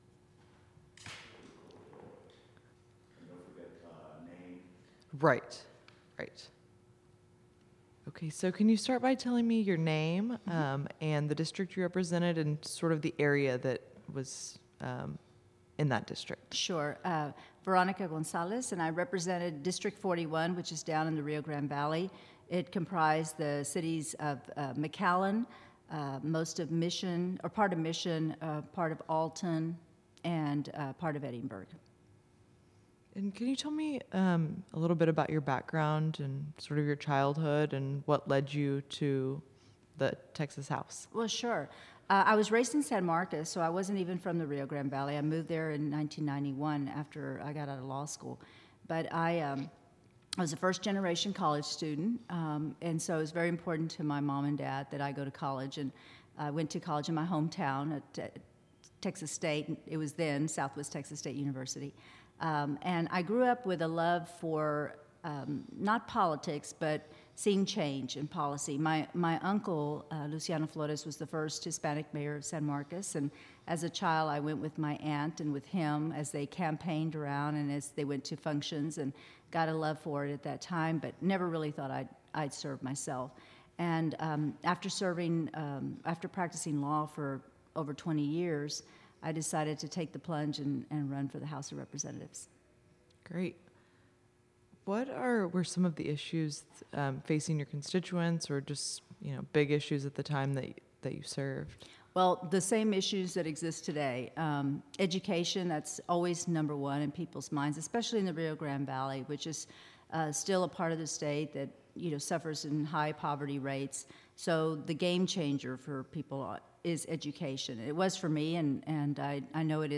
Texas House of Representatives . Oral history interview with Veronica Gonzales, 2017.